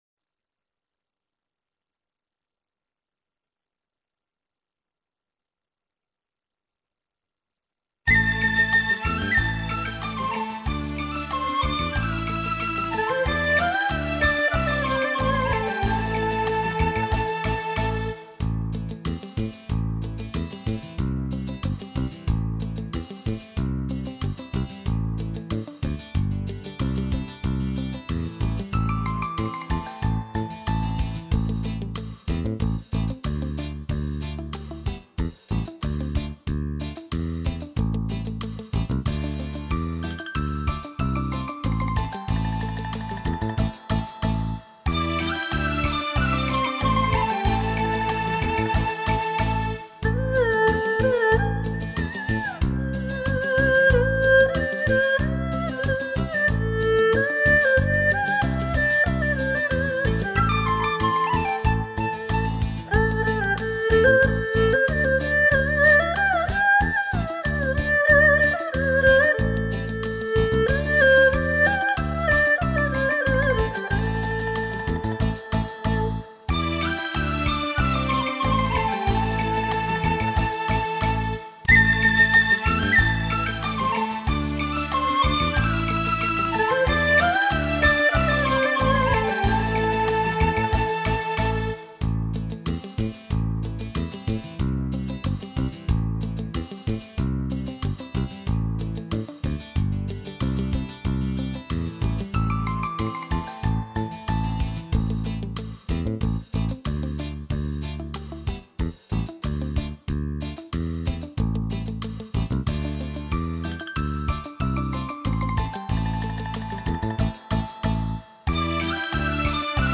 0083-葫芦丝曲：赶马调1.mp3